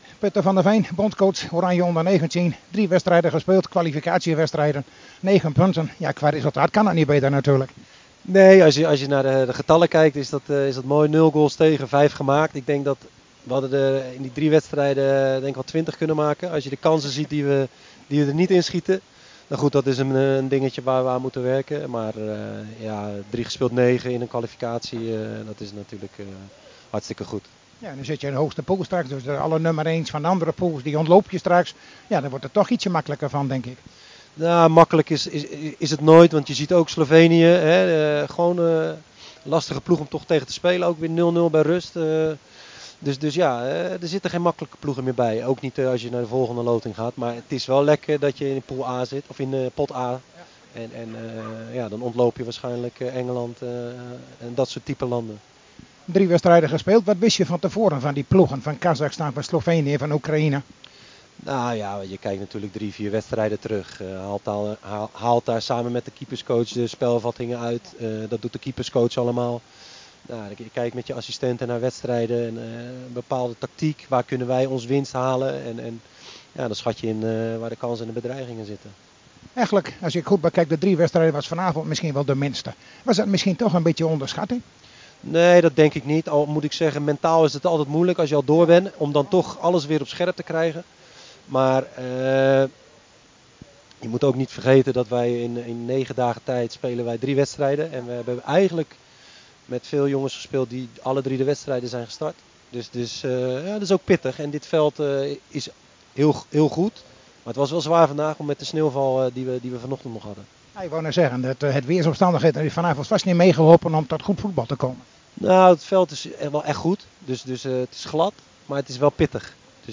Nederland o19 als groepswainnaar door naar eliteronde verslag, foto's en interviews